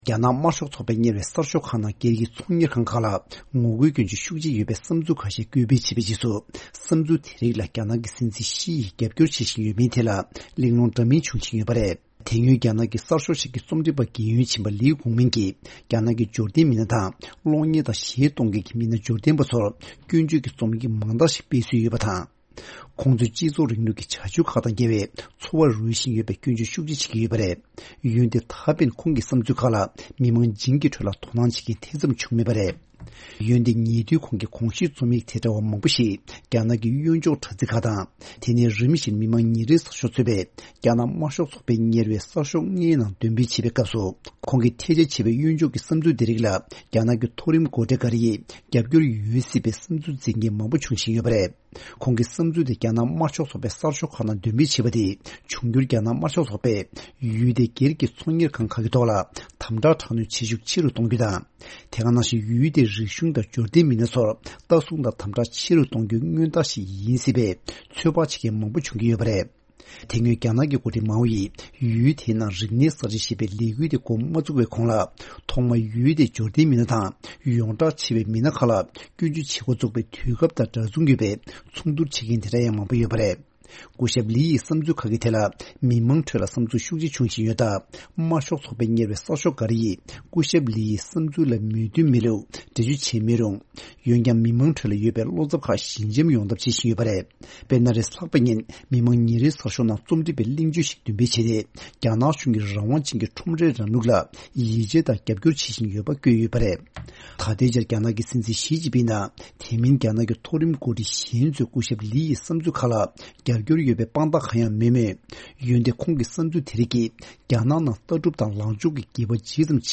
སྙན་སྒྲོན་ཞུ་ཡི་རེད།།